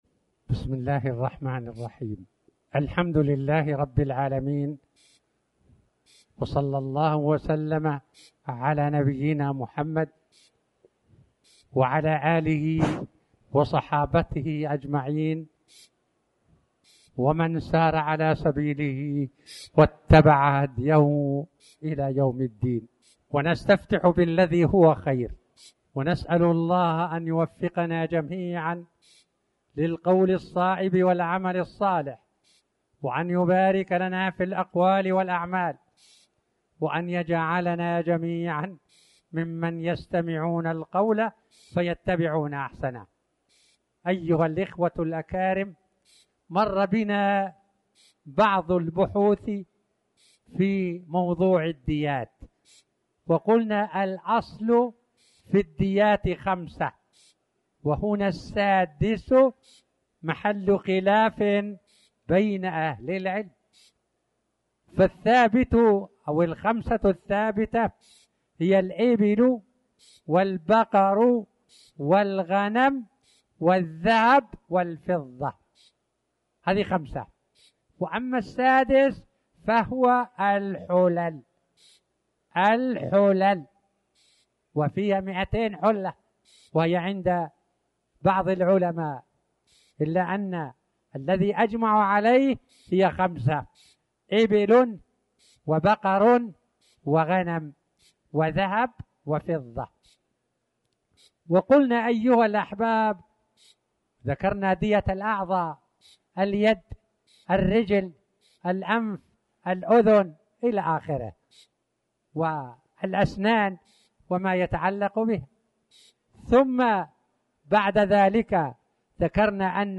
تاريخ النشر ٢٤ شوال ١٤٣٩ هـ المكان: المسجد الحرام الشيخ